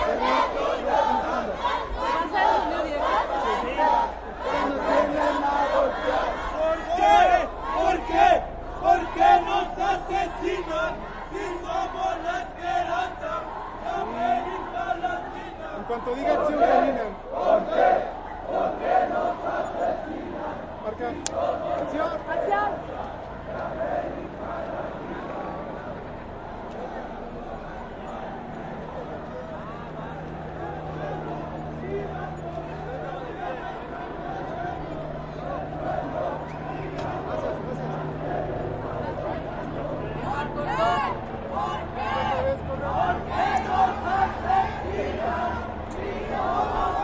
Tag: 人群 游行 示威 聚众